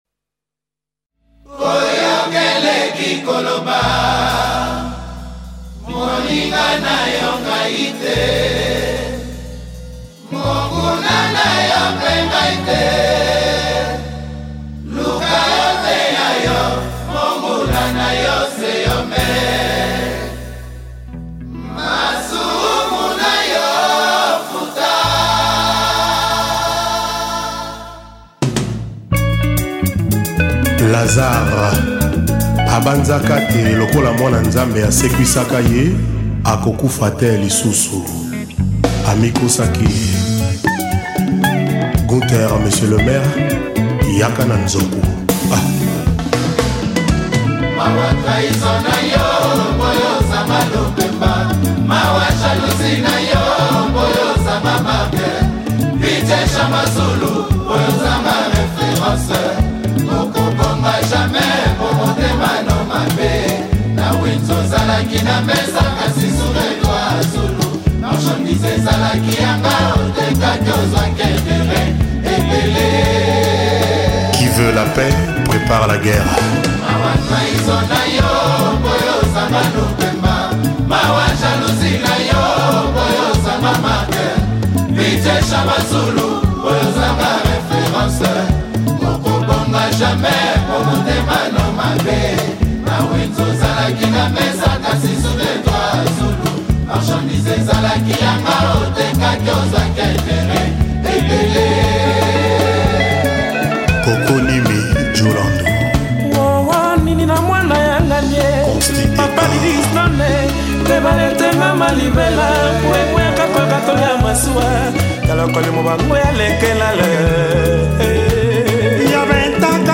AudioCongoZilipendwa